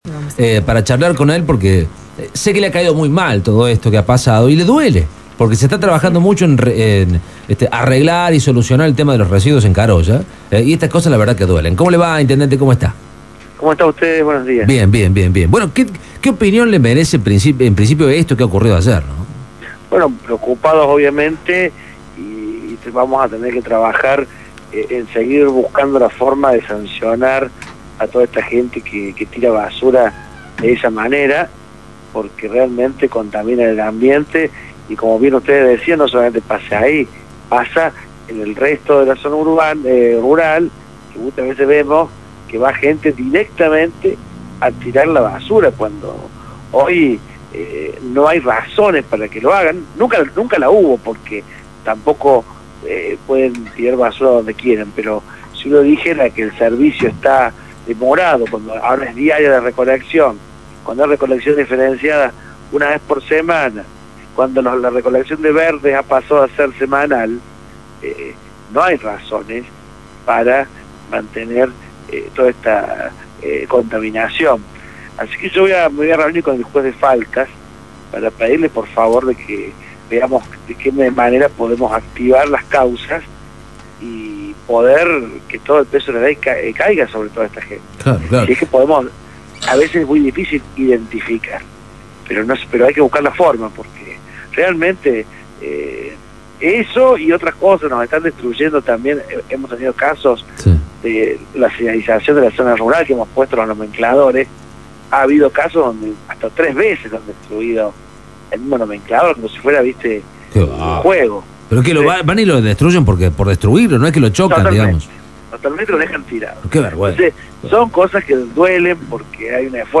AUDIO: Gustavo Brandan, intendente de Colonia Caroya.